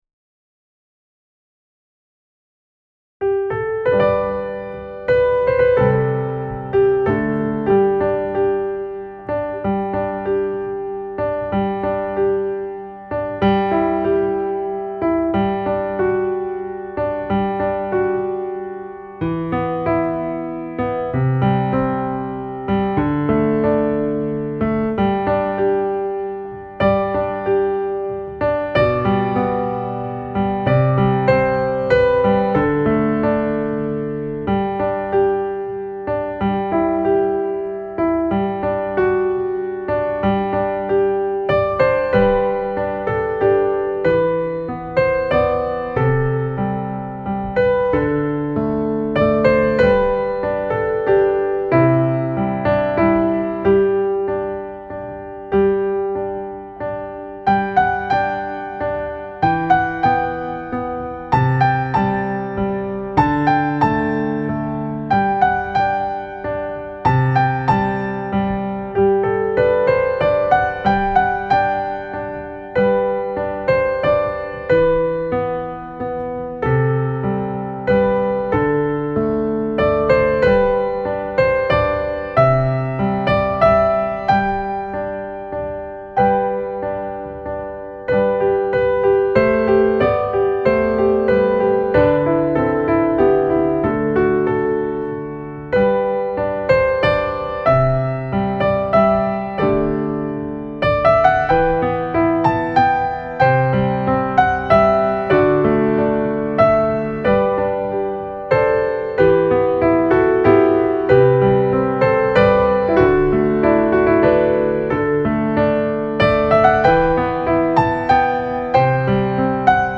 Piano Accompaniment Track